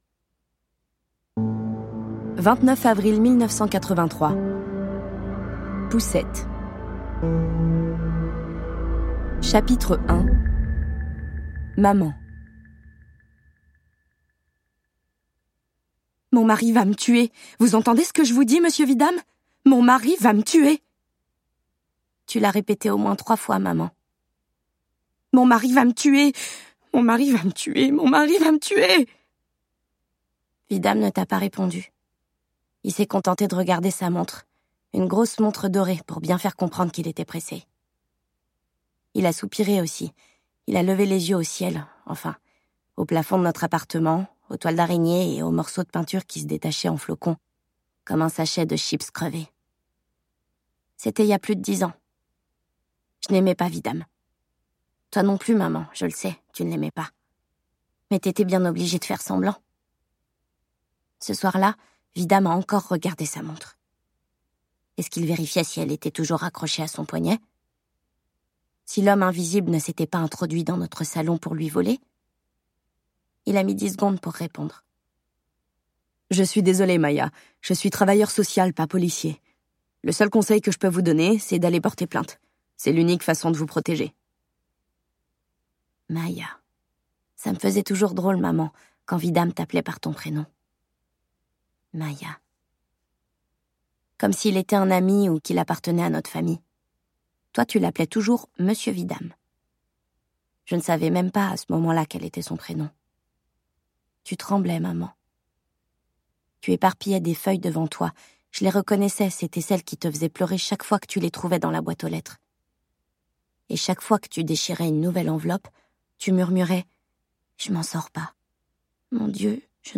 Télécharger le fichier Extrait MP3